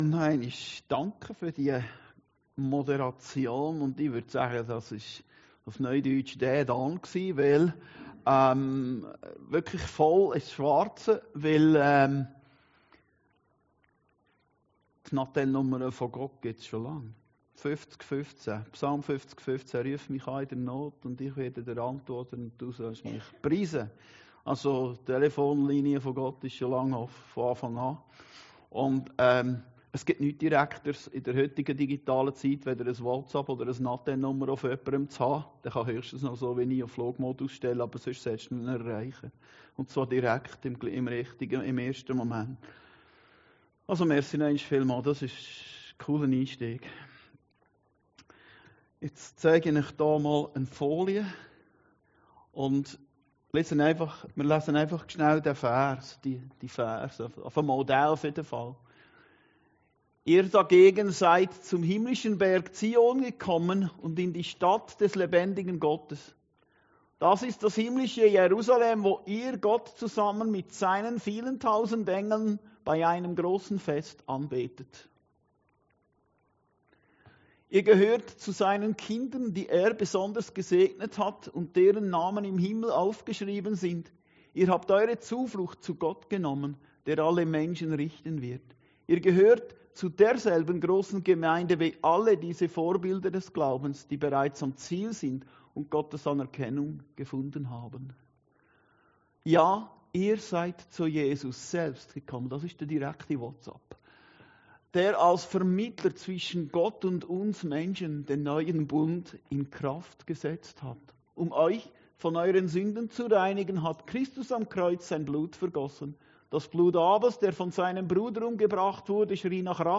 Predigten Heilsarmee Aargau Süd – AUGENÖFFNER GESCHICHTEN 4